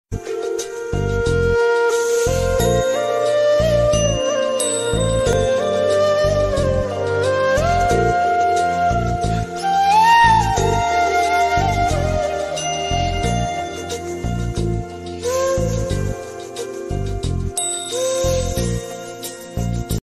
• Pure flute instrumental
• Calm and non-irritating sound
High-quality MP3 with clear flute sound.